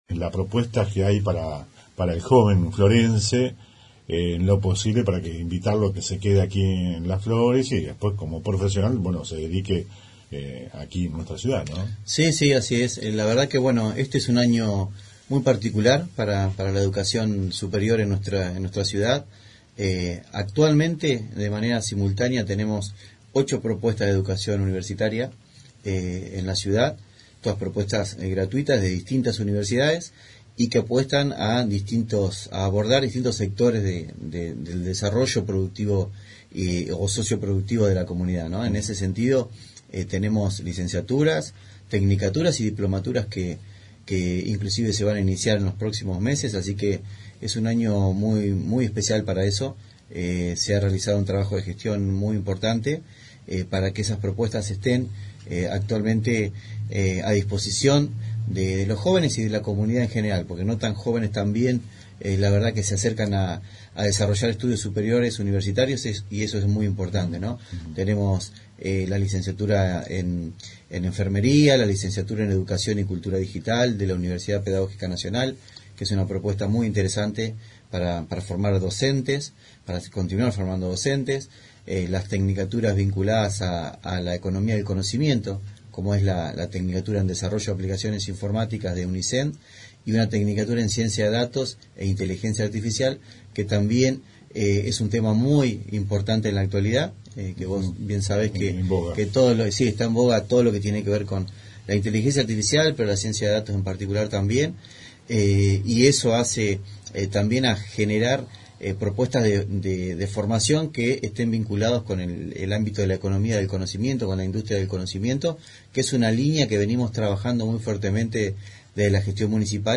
En el Periodístico del viernes, hablamos con el referente de la cartera de educación profesor Rogelio Diaz. En una entrevista que abarcó 26 minutos, nos contó sobre la propuesta educativa.